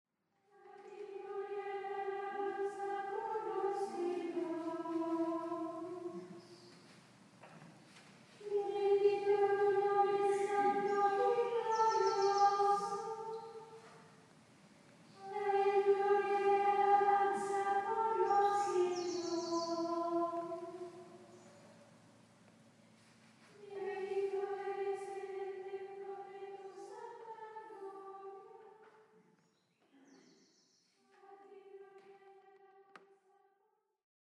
IT was 6am when the first bells for morning prayer rang out over the valley.
There, a little sea of white veils capping blue garments greeted me with their ethereal morning chant.
MorningPrayerBirds.mp3